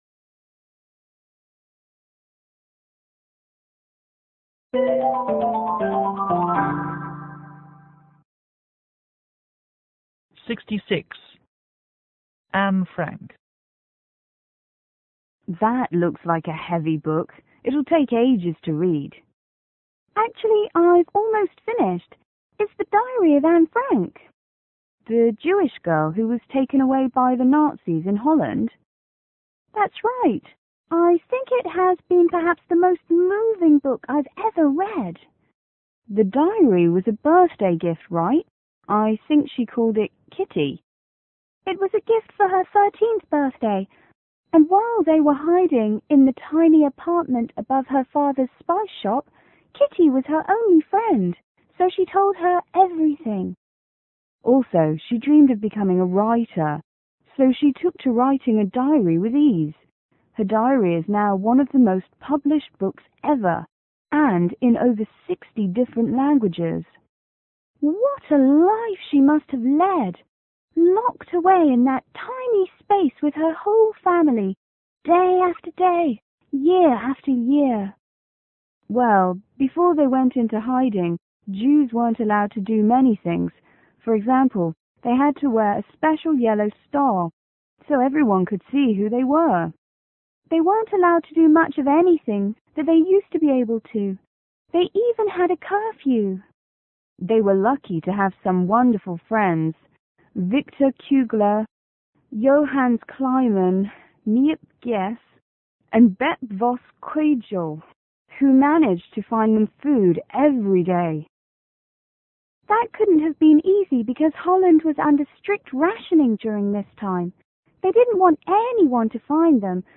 W1：Woman 1  W2：Woman2